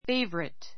favorite 小 A1 féivərit ふェ イヴァリ ト 名詞 お気に入り , 人気者 形容詞 お気に入りの , 大好きな ⦣ 名詞の前にだけつける. my favorite subject [dish] my favorite subject [dish] 私の大好きな教科[料理] Who is your favorite ball player?